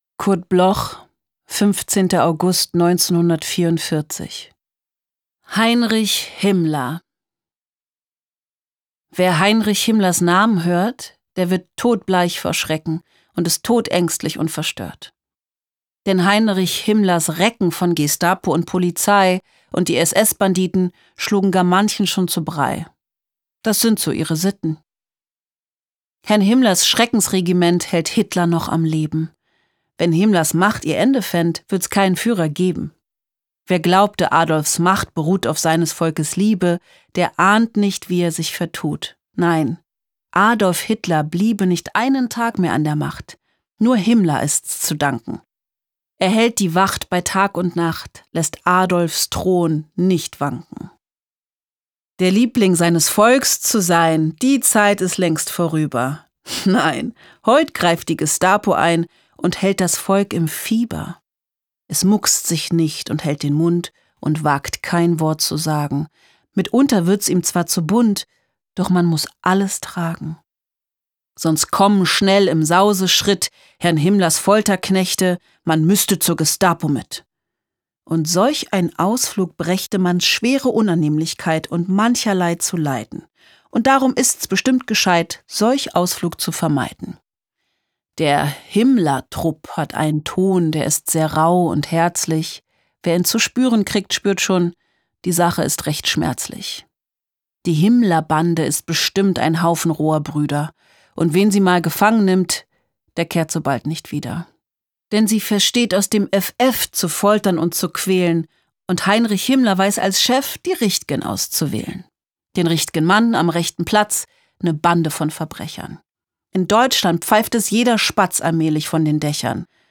Recording: Speak Low, Berlin · Editing: Kristen & Schmidt, Wiesbaden